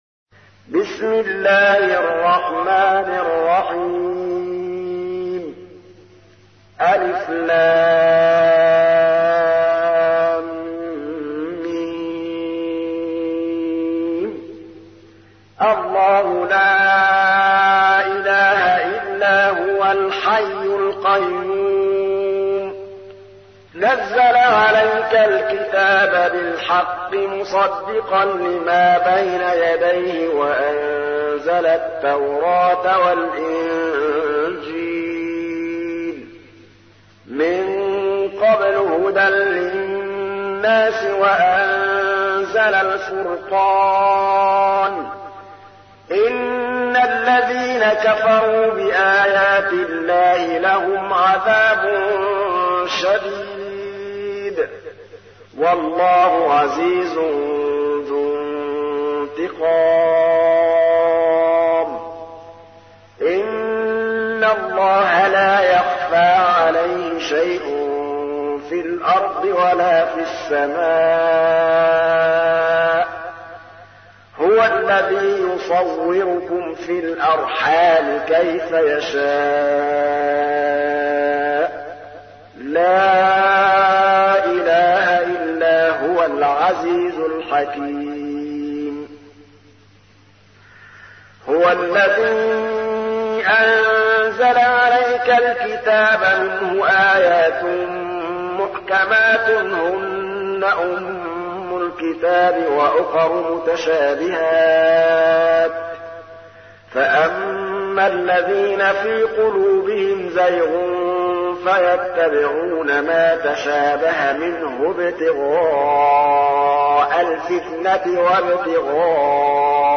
تحميل : 3. سورة آل عمران / القارئ محمود الطبلاوي / القرآن الكريم / موقع يا حسين